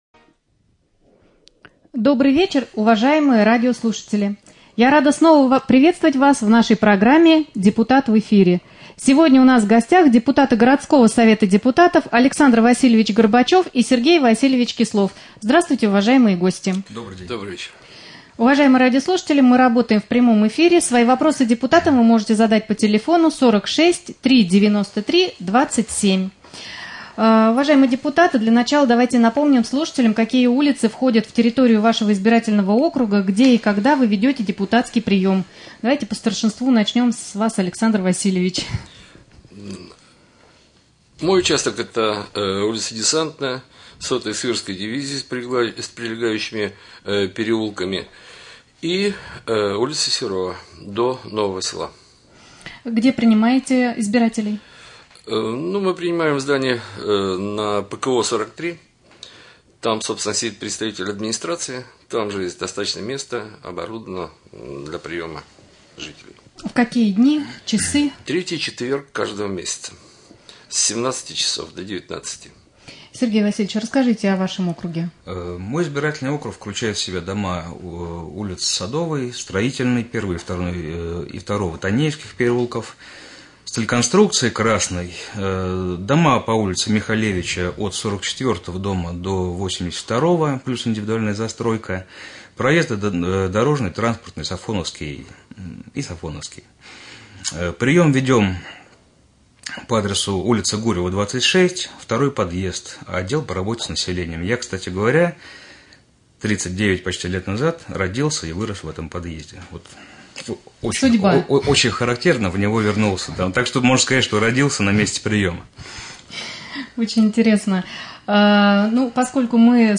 Прямой эфир. Гости студии депутаты городского совета депутатов Горбачев Александр Васильевич и Кислов Сергей Васильевич.